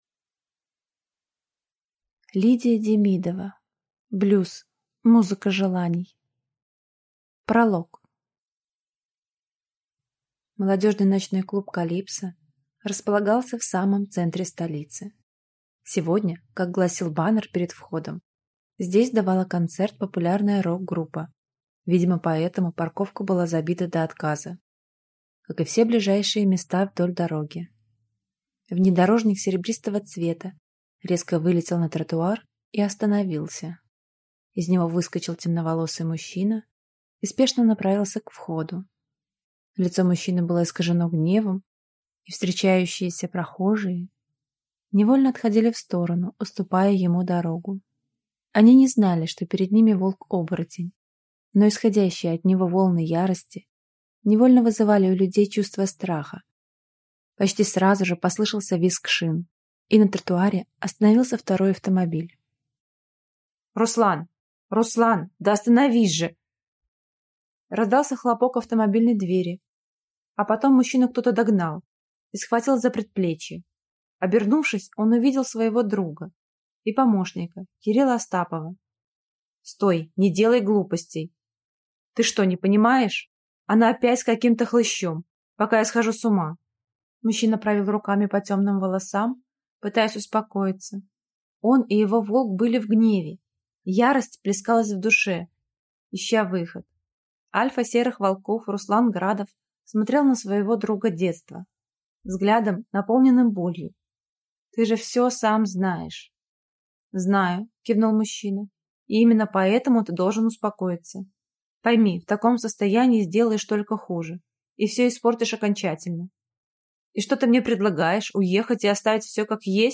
Аудиокнига Блюз. Музыка желаний | Библиотека аудиокниг